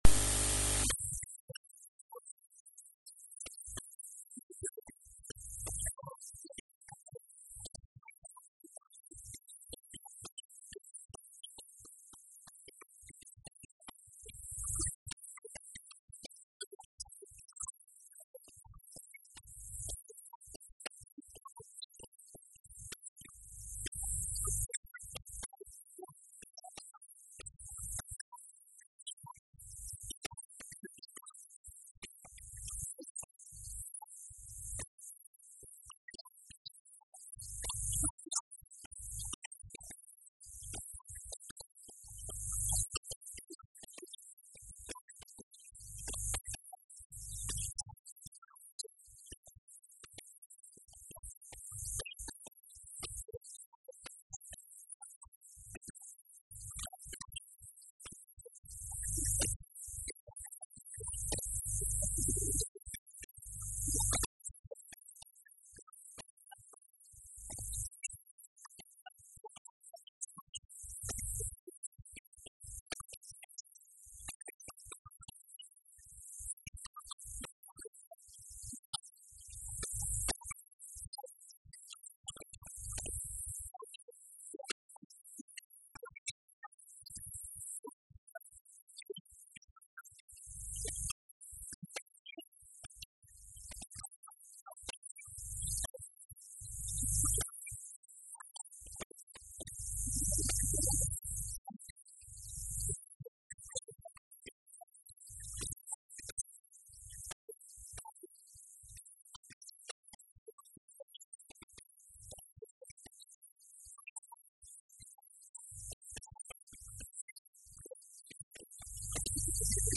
Luiz Fagundes Duarte falava na Assembleia Legislativa, durante a discussão de um projeto de Resolução denominado 'Frente Comum em Defesa da Universidade dos Açores', apresentado pelo PS, que foi aprovado por unanimidade.